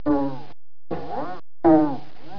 دانلود صدای کارتون 52 از ساعد نیوز با لینک مستقیم و کیفیت بالا
جلوه های صوتی
برچسب: دانلود آهنگ های افکت صوتی اشیاء دانلود آلبوم صداهای کارتونی از افکت صوتی اشیاء